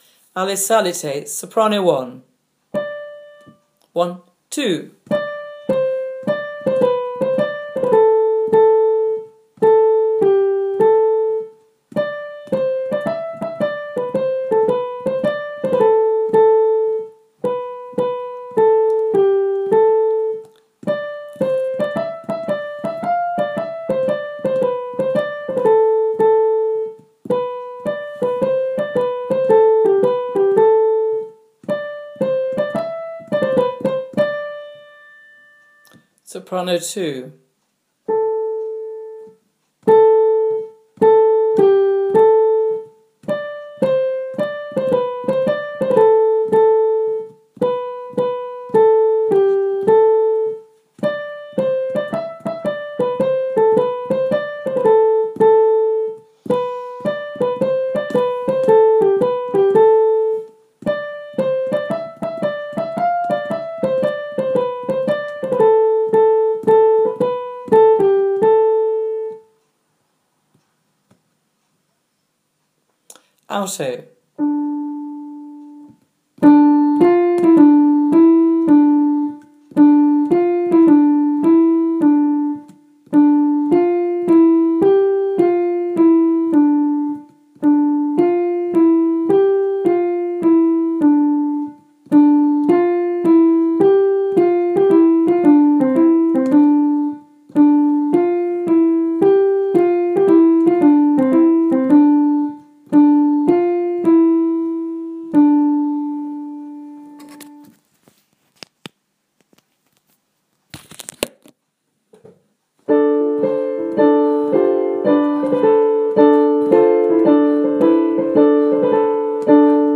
Anon - Alle psalite - S1 S2 A